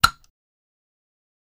Toy Gun | Sneak On The Lot